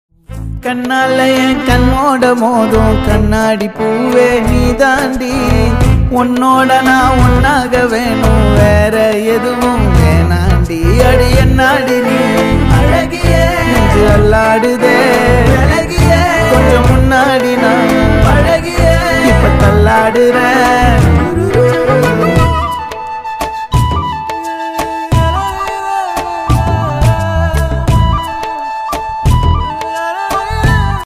📁 Category Tamil Ringtones